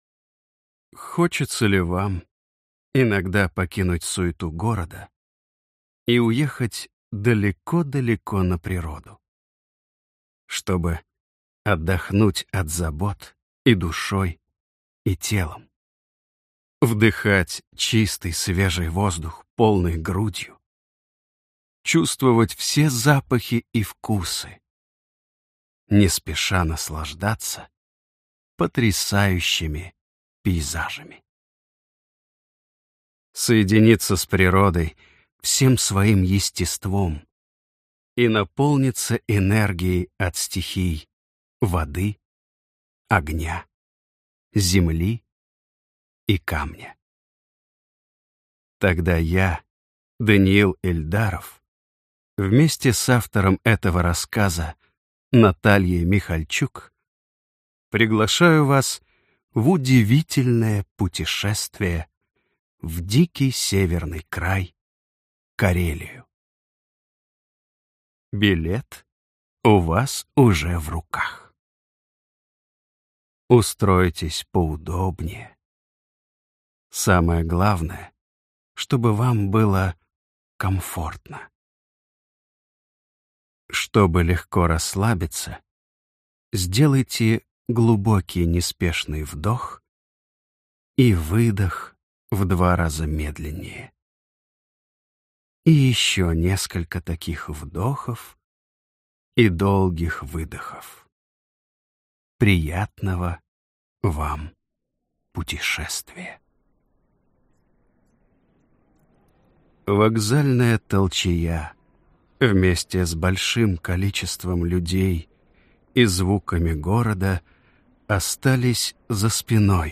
Аудиокнига Карельские каникулы | Библиотека аудиокниг
Прослушать и бесплатно скачать фрагмент аудиокниги